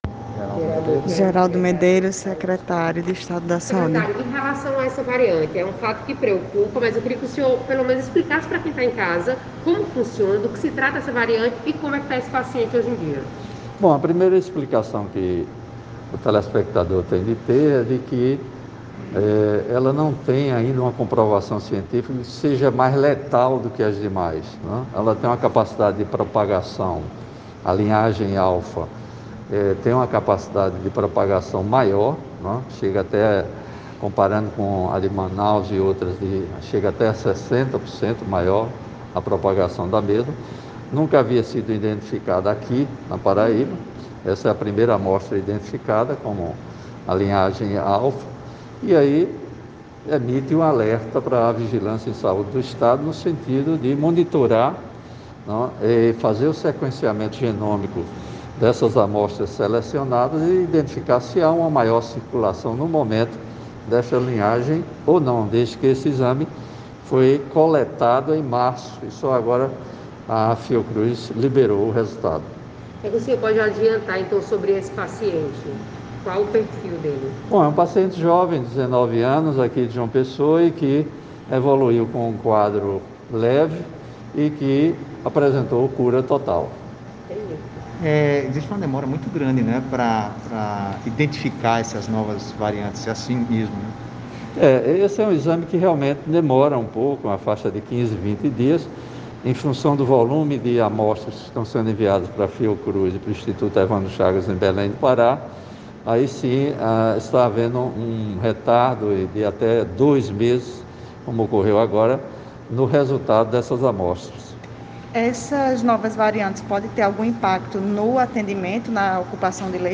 Ouça o áudio com explicações do secretário estadual de Saúde, Geraldo Medeiros:
audio-geraldo-medeiros.ogg